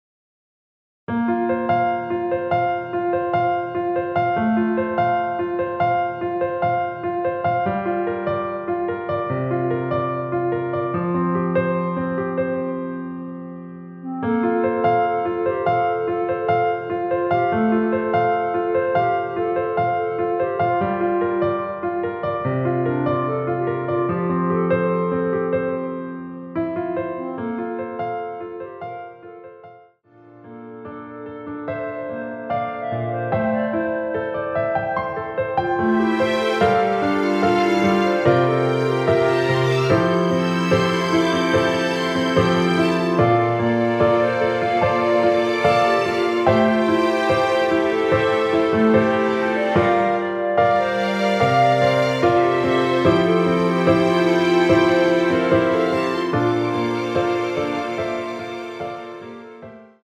원키에서(-7)내린 멜로디 포함된 MR입니다.
Bb
앞부분30초, 뒷부분30초씩 편집해서 올려 드리고 있습니다.
중간에 음이 끈어지고 다시 나오는 이유는